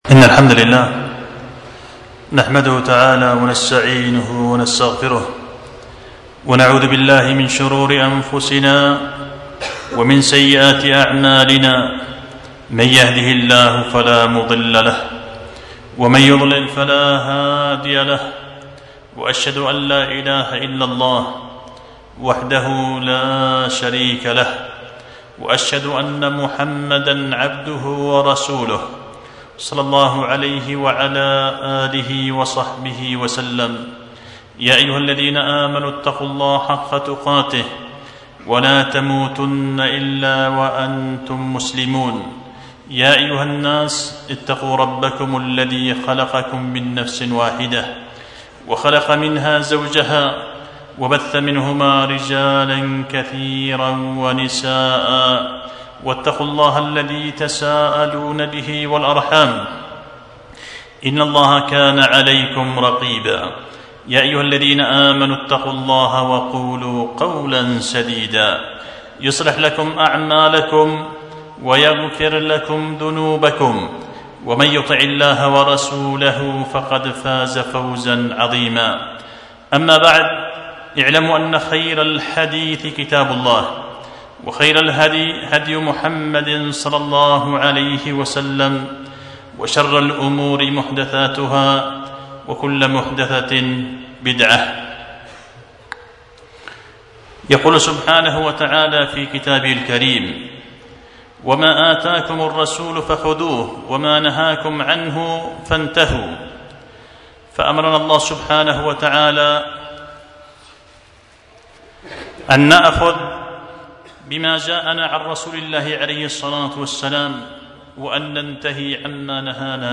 خطبة جمعة بعنوان البيان لما يتعلق بنصف شعبان